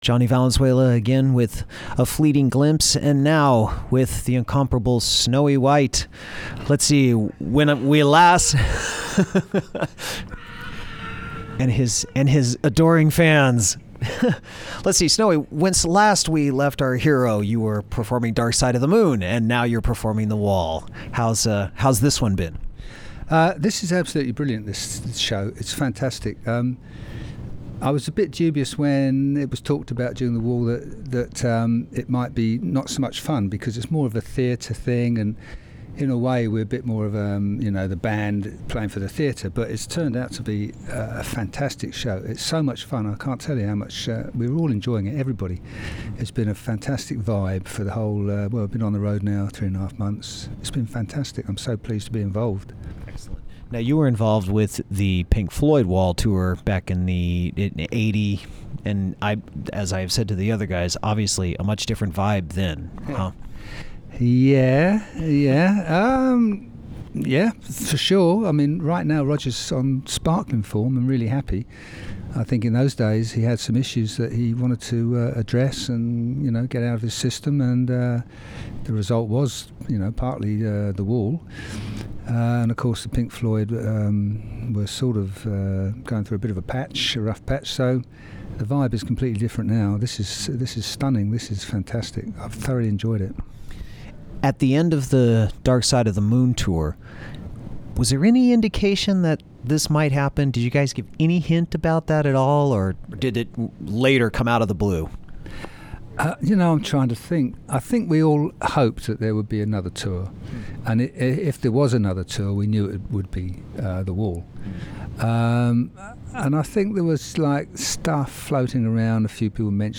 Interview with Snowy White